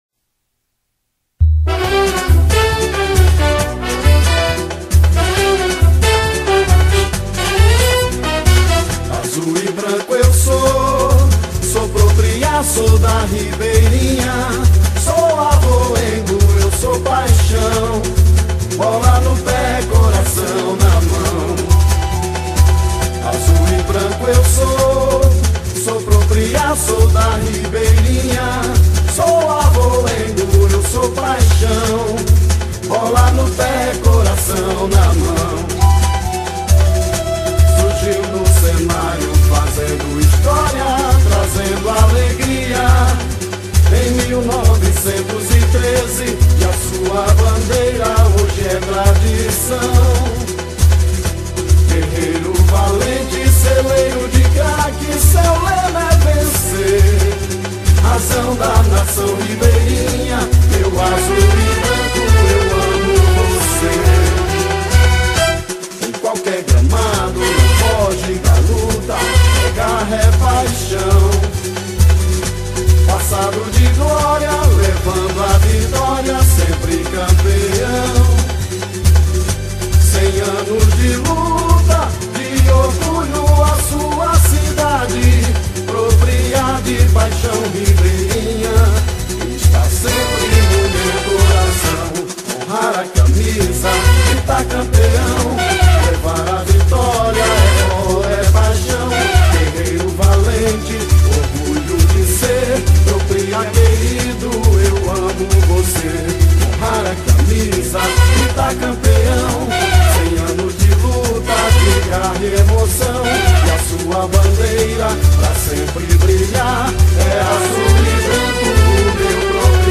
Hino-do-Propria-Legendado.mp3